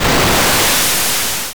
Wave_Crash.wav